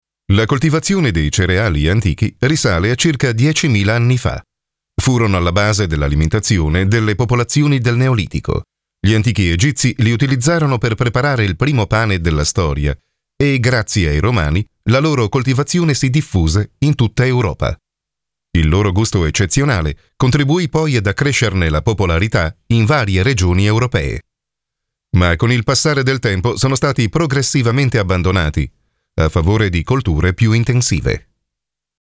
Narração
Documentários
Posso descrever minha voz como uma voz masculina de "meia-idade", profunda, calorosa, comunicativa, para documentários ou onde o sentimento é necessário.
Barítono